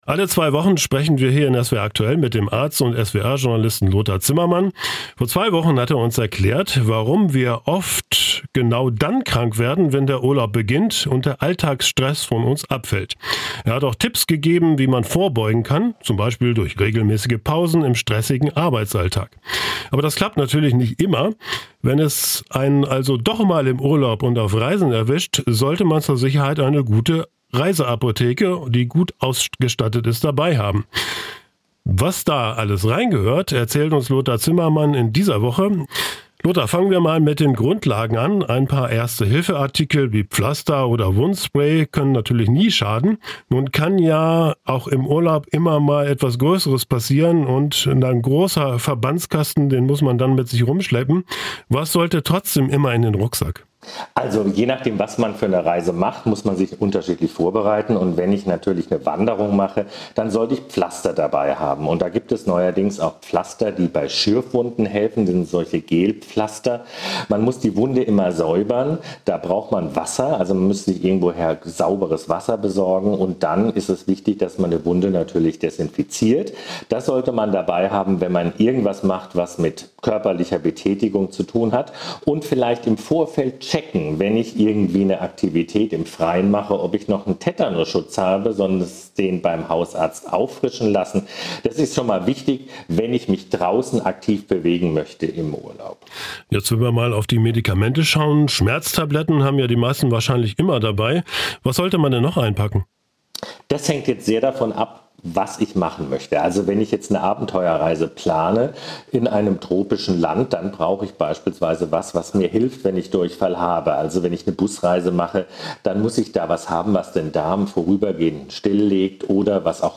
"SWR Aktuell Im Gespräch" - das sind Interviews mit Menschen, die etwas zu sagen haben.